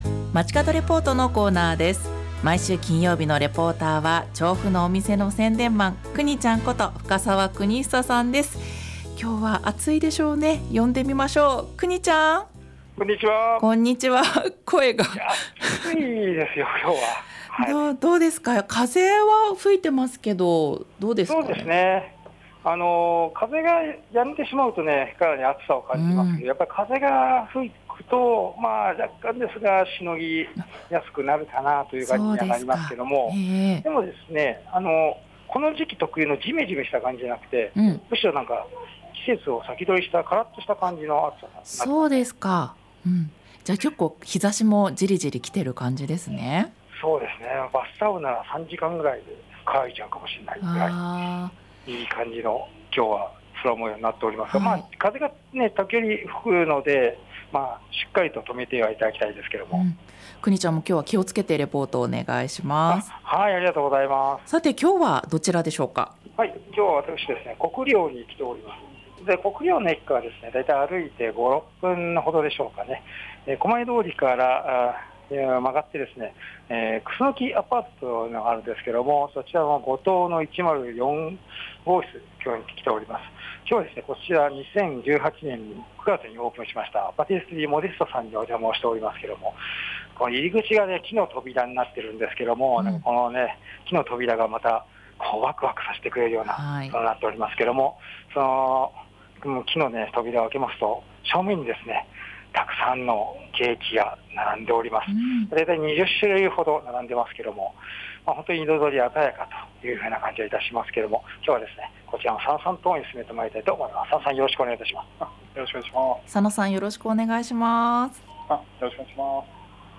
午後のカフェテラス 街角レポート
本日の中継でノイズが入っておりました。